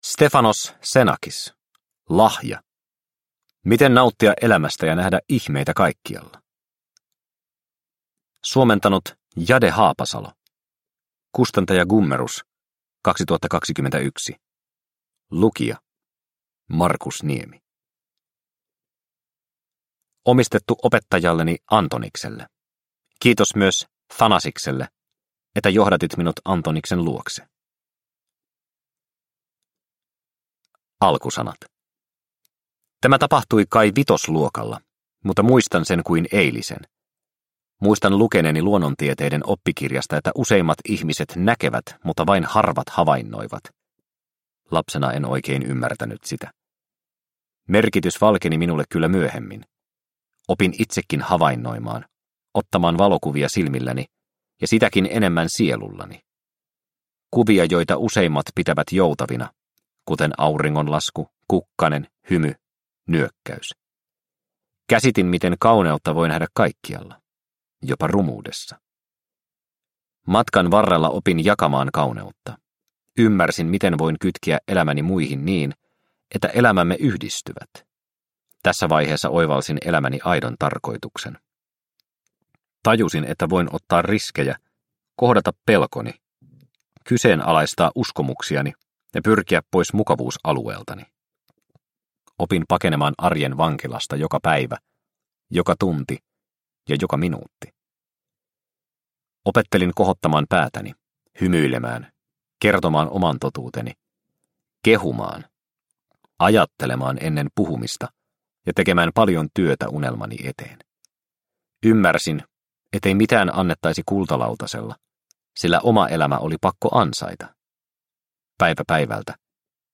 Lahja – Ljudbok – Laddas ner